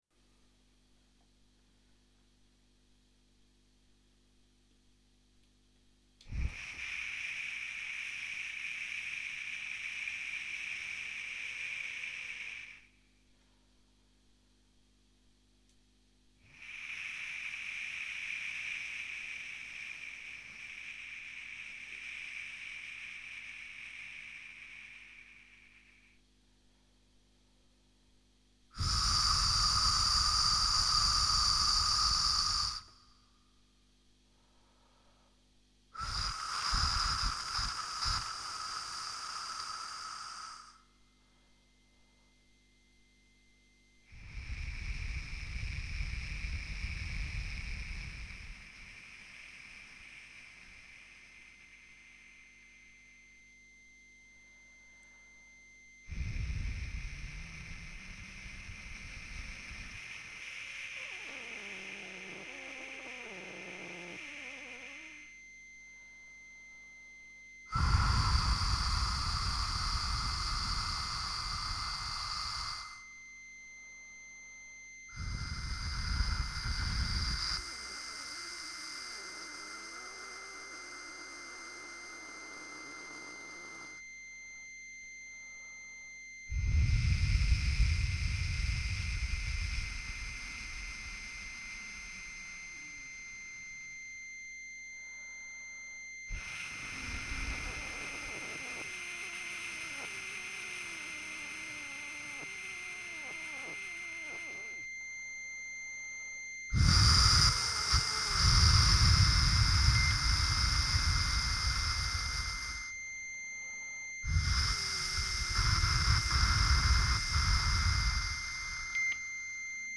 guitare électrique